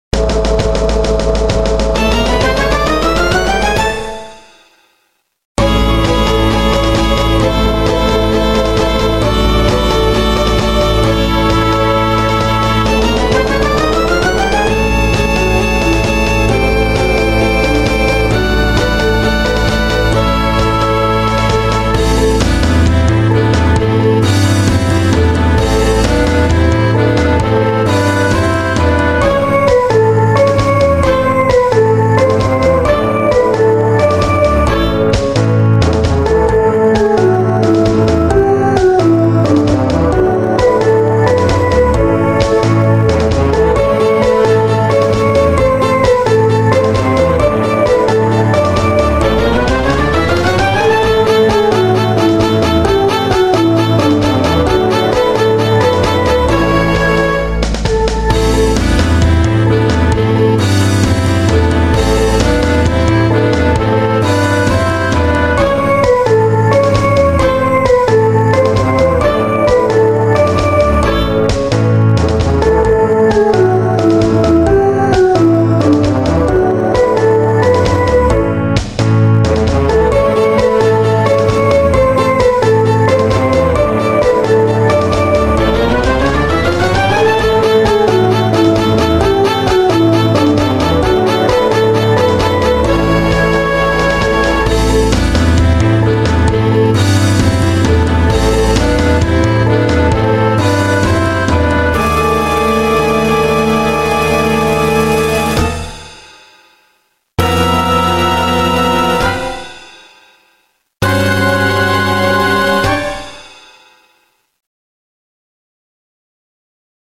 The music is scored for a 16 piece orchestra and at times has 9 individual characters singing together on stage.
Please excuse the vocals on “The Bright Life” tunes being computerized ohs and ahs.
The Bright Life 1-4 Gangsters In Motion Musical Theater